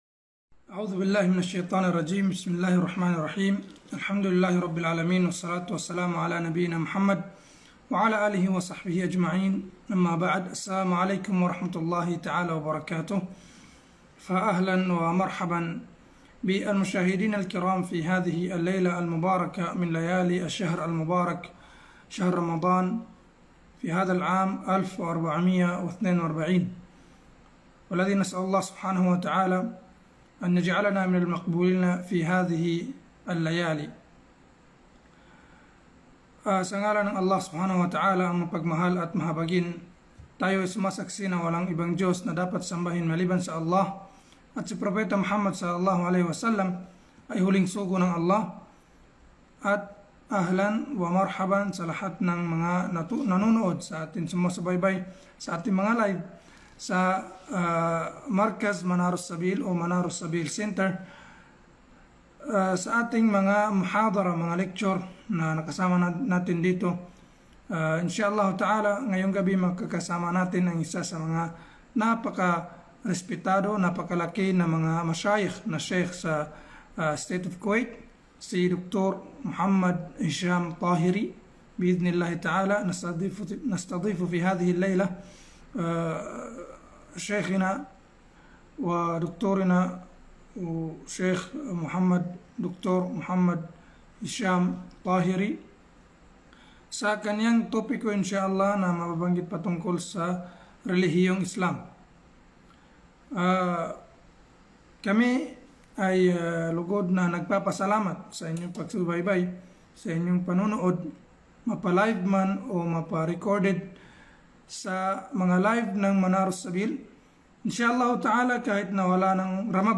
محاضر - الهدف من الخلق (ترجمة فورية للغة الفلبينية)